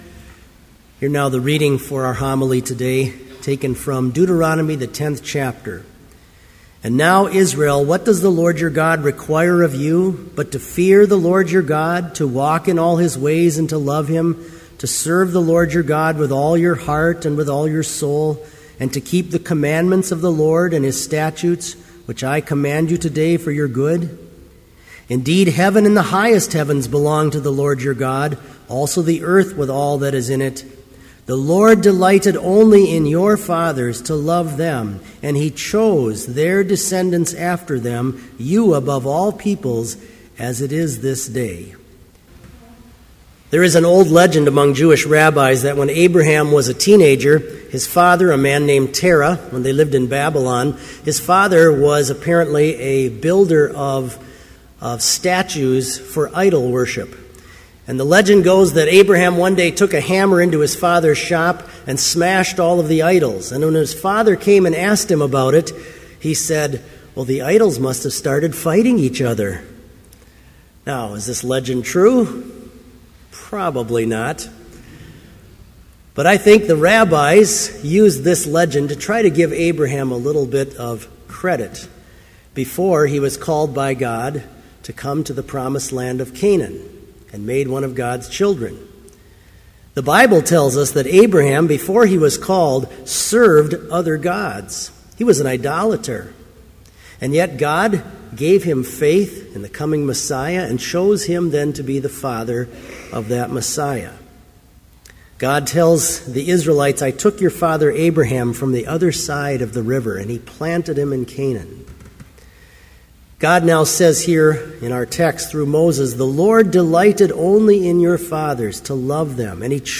Complete Service
• Hymn 490, vv. 1-4, These Are the Holy Ten Commands
• Homily
This Chapel Service was held in Trinity Chapel at Bethany Lutheran College on Tuesday, October 1, 2013, at 10 a.m. Page and hymn numbers are from the Evangelical Lutheran Hymnary.